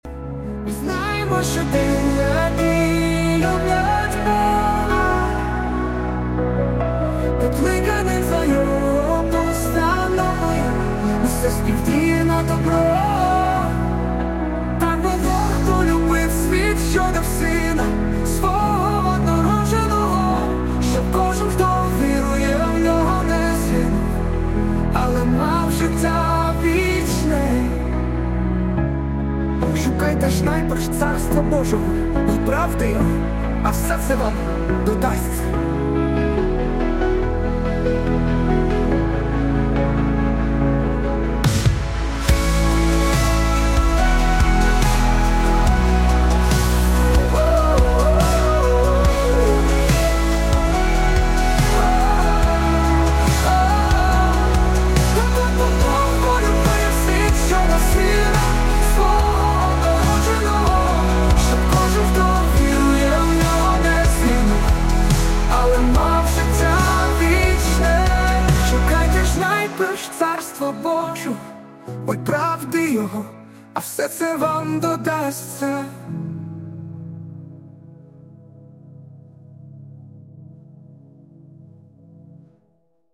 песня ai
Jesus Worship